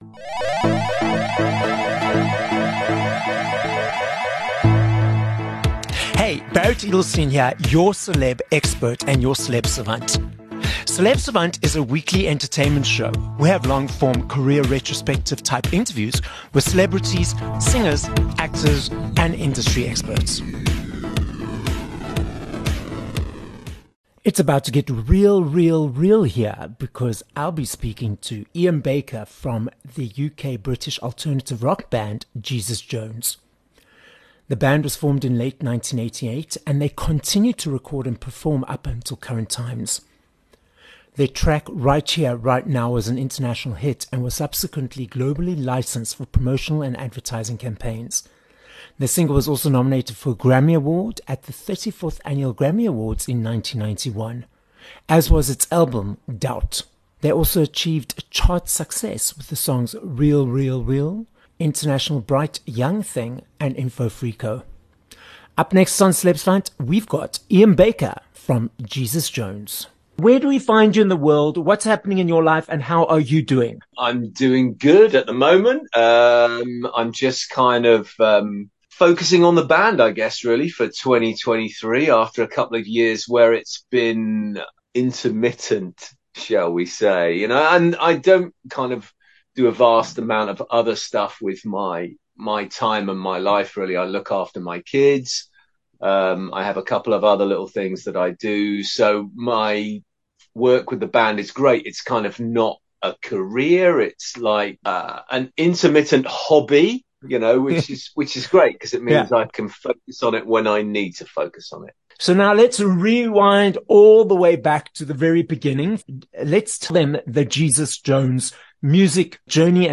26 Apr Interview with Iain Baker (from Jesus Jones)